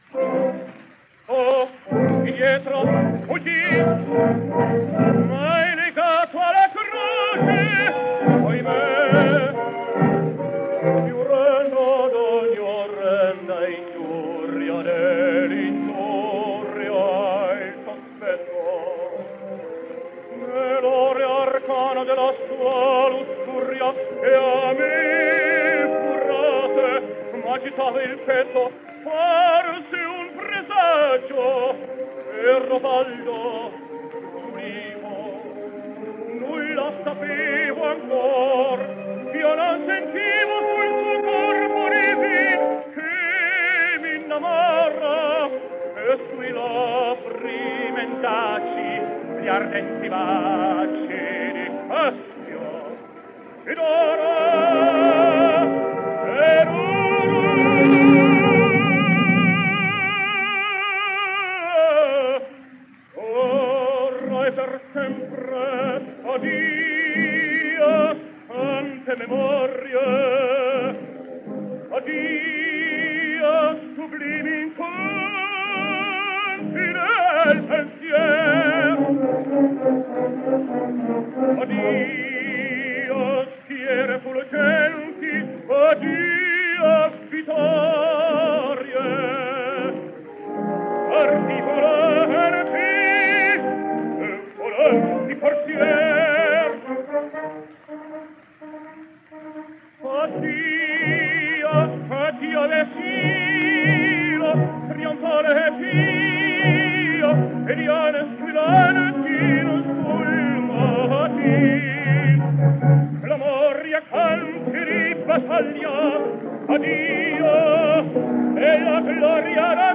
Here are some scarce Odeon records then, from this, his Metropolitan Opera debut made in 1912, during his period in America and sung in Italian, you’ll notice.